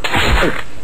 a venus flytrap1.ogg